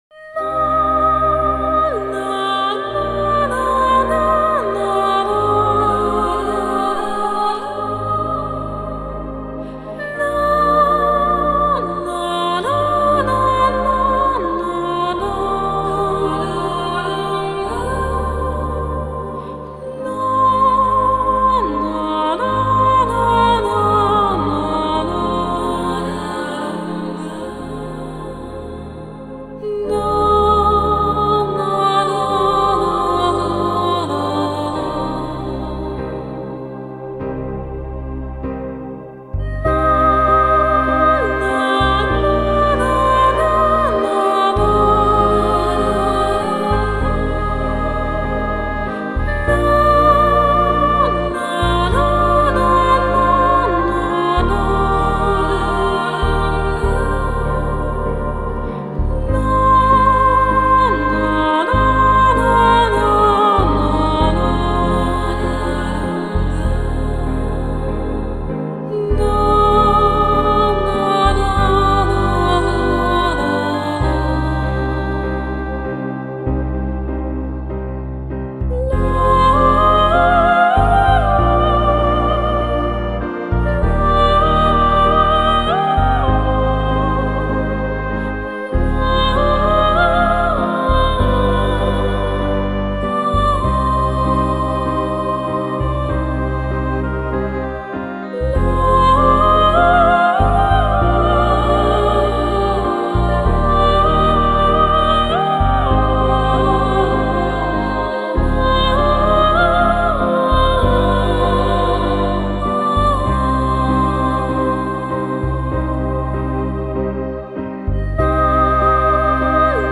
给我们带来了浪漫、忧郁、空灵飘渺的跨界听觉享受。
旋律优美歌词极其简单